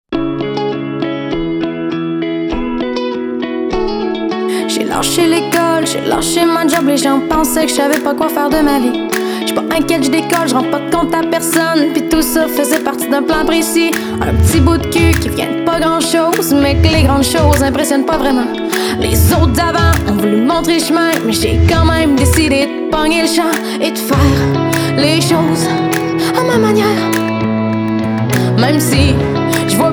Musique francophone